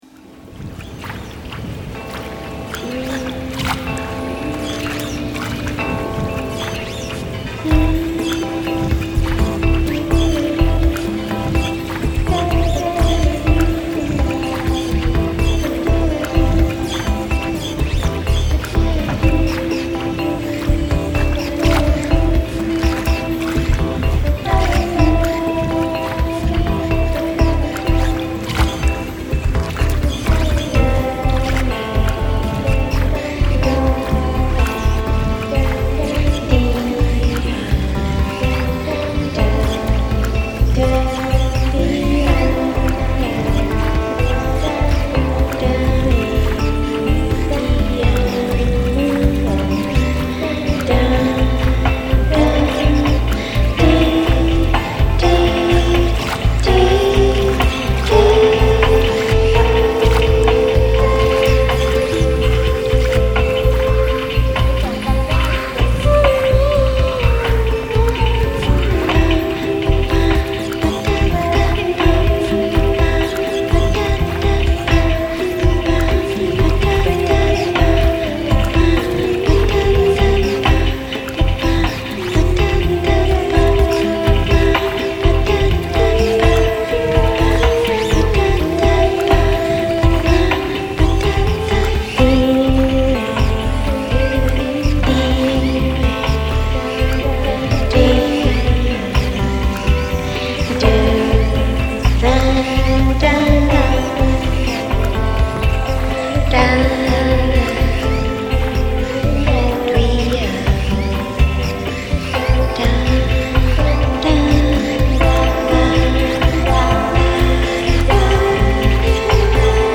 vo.+re-mix